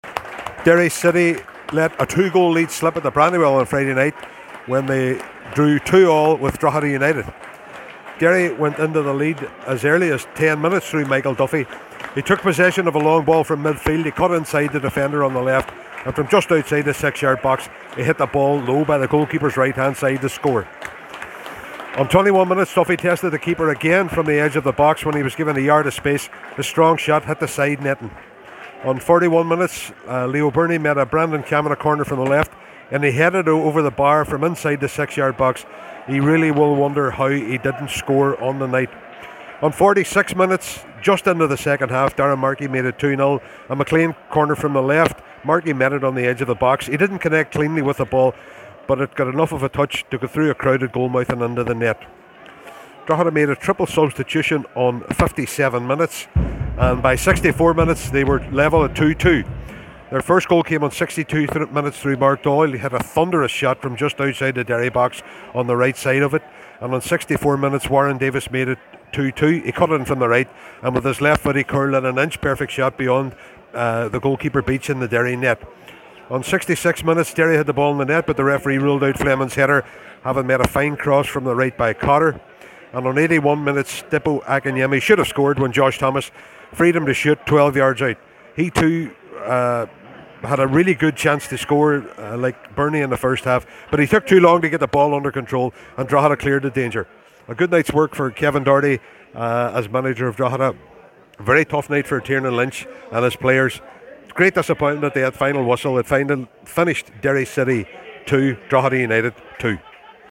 full time report from The Brandywell…